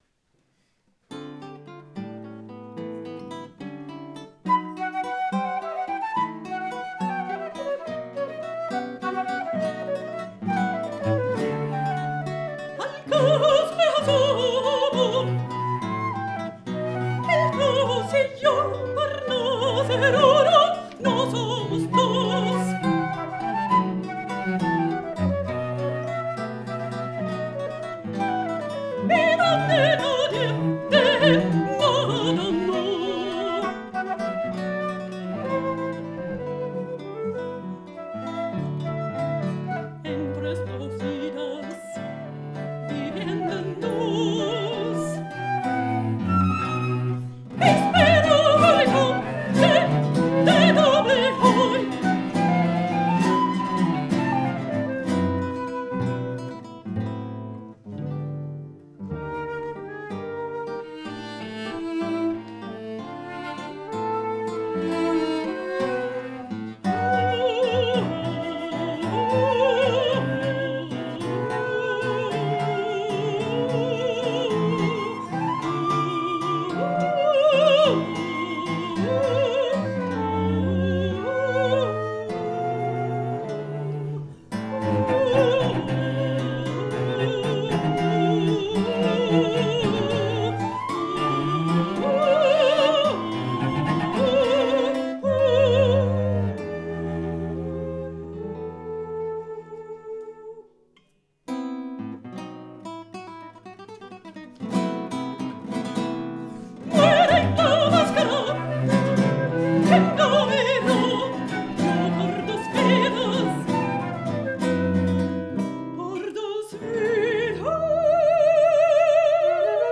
mezzosoprano
flauto
violoncello
chitarra
Archivio Storico della Città di Torino
Live recording, Giugno 2007